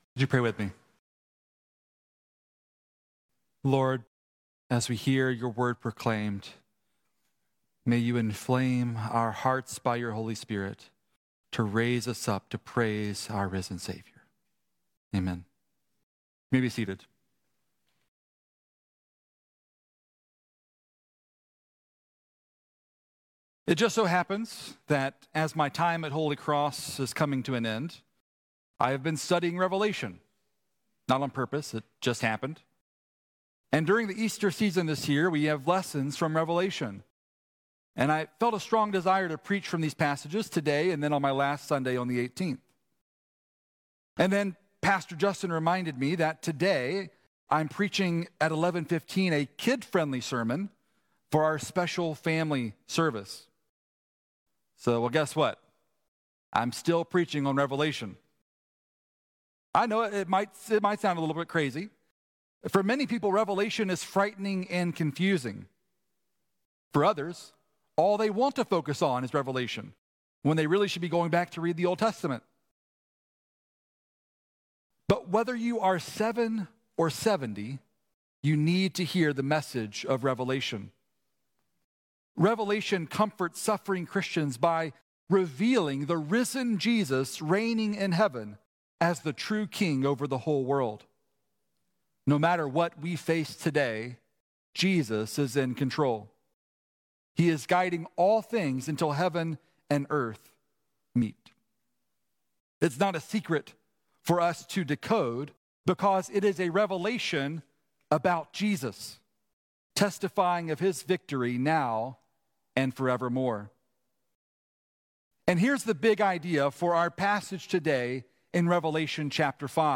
Sermons - Holy Cross Anglican Cathedral